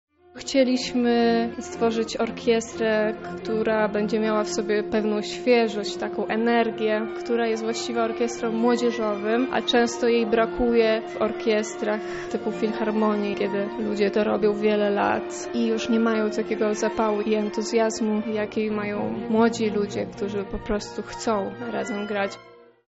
Koncert na skrzypce, wiolonczele i trzy bisy. Ponad trzydzieści osób, skrzykniętych na facebooku wzięło smyczki w swoje ręce.